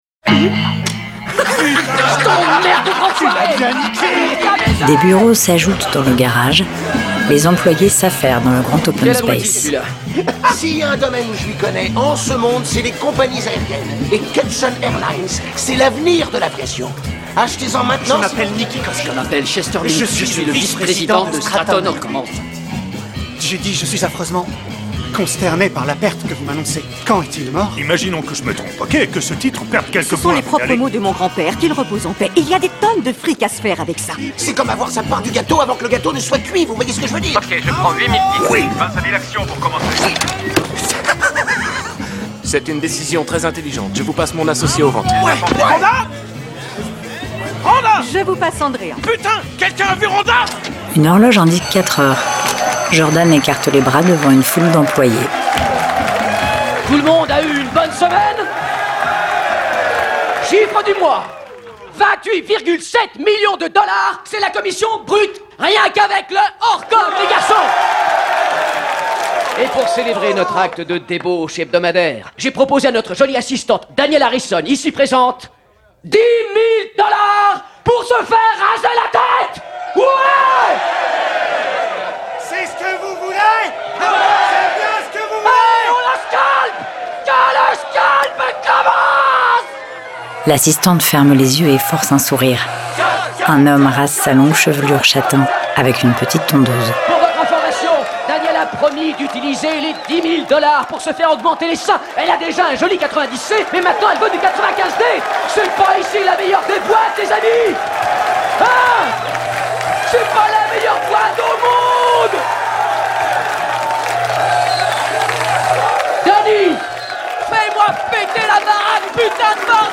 AD Loup de Wall Street démo